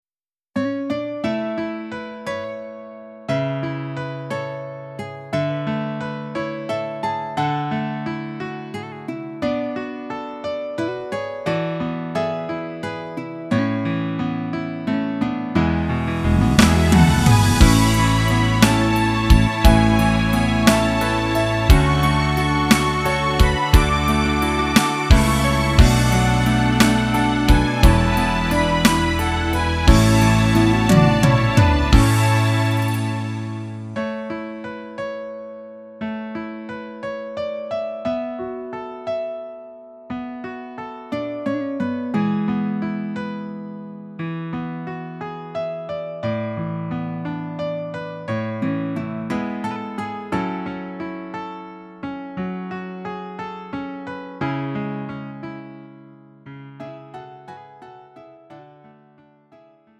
음정 -1키 4:48
장르 가요 구분 Lite MR